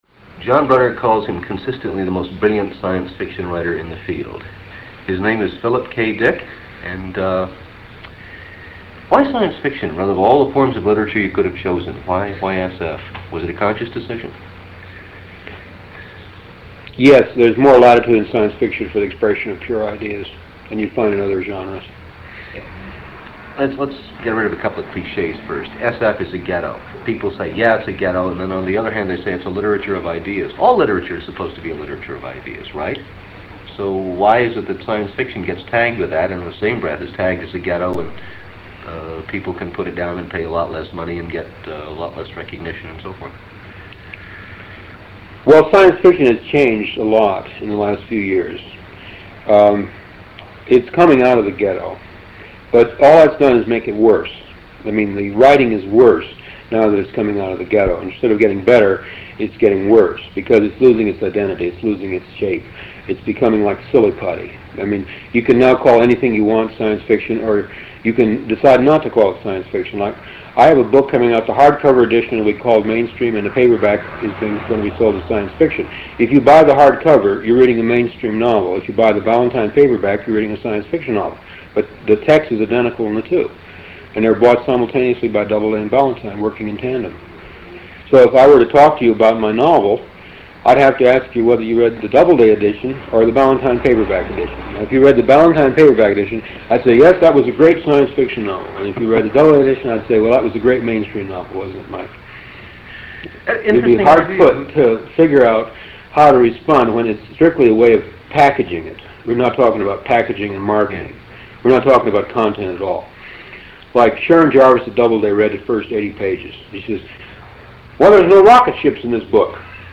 Interview with Philip K Dick 1